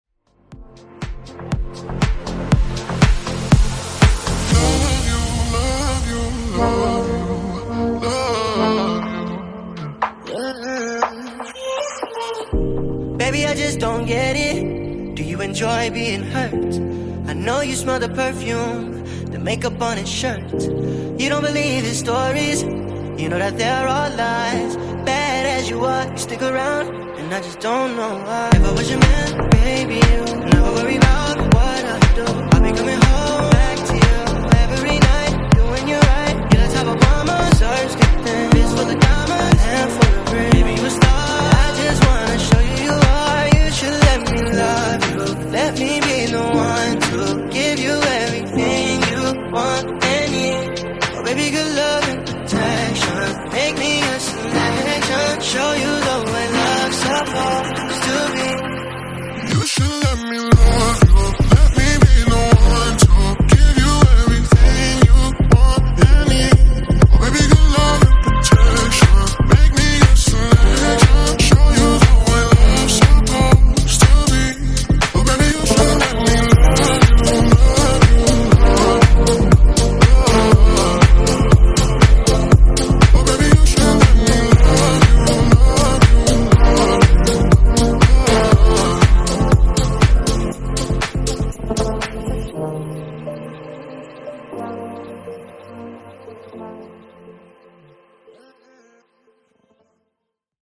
Genre: EDM
Clean BPM: 125 Time